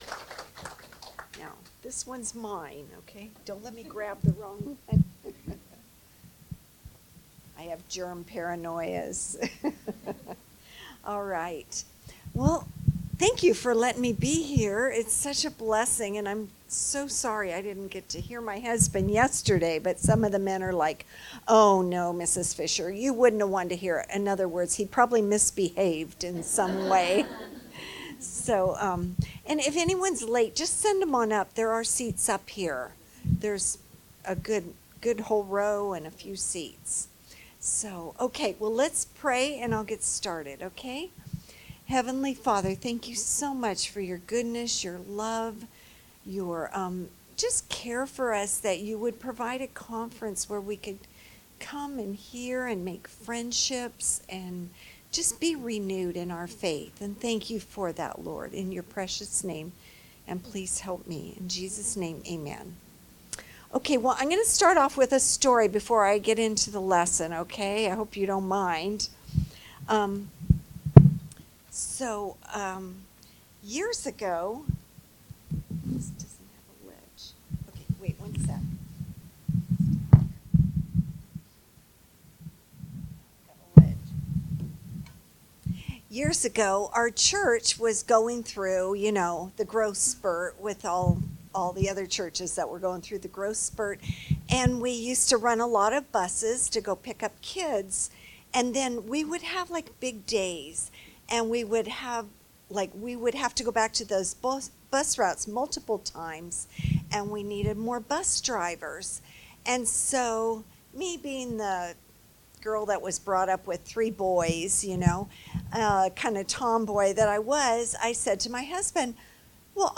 Wed AM Ladies Session Leadership Conference 2025